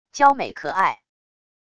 娇美可爱wav音频